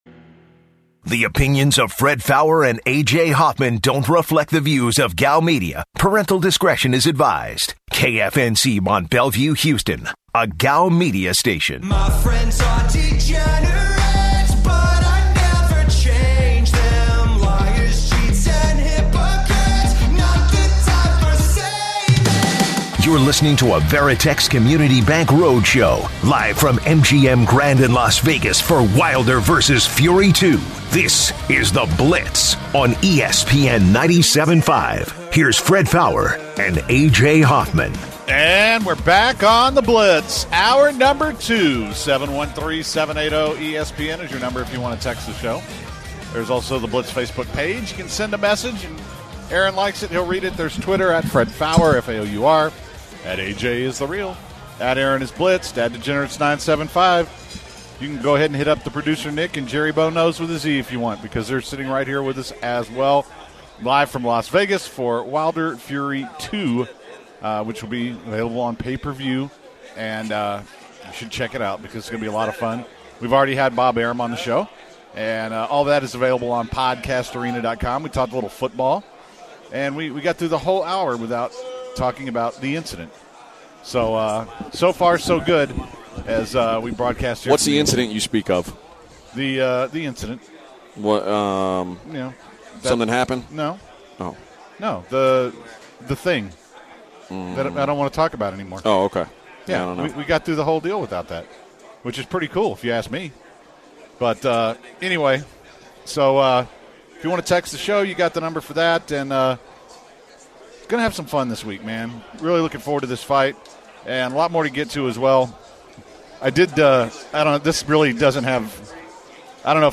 Also up this hour, why are NFL players smuggling hundreds of pounds of weed across the country ? We also have ESPN and ABC sportscaster Joe Tessitore live on radio row today to have a look at Fury/Wilder this weekend.